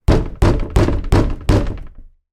Звуки стука по столу
На этой странице собраны различные звуки стука по столу – от резких ударов кулаком до легкого постукивания пальцами.
Стук по старому деревянному столу